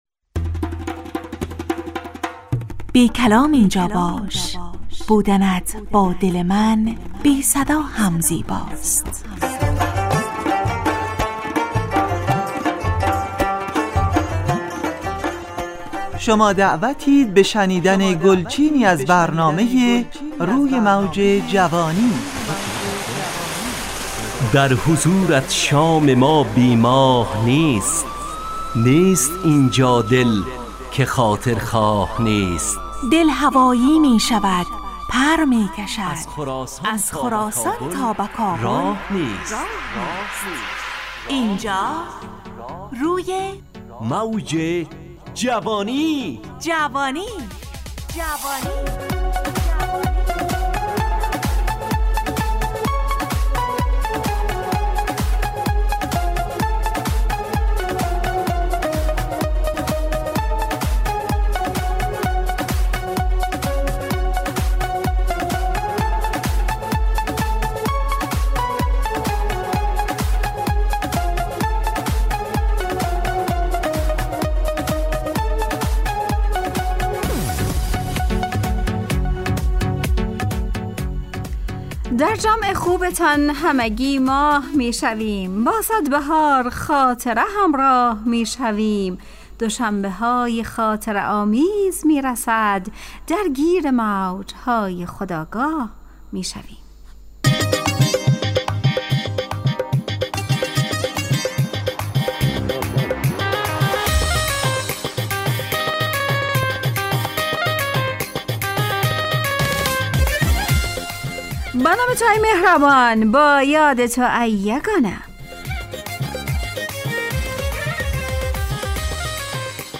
همراه با ترانه و موسیقی مدت برنامه 70 دقیقه . بحث محوری این هفته (امانت)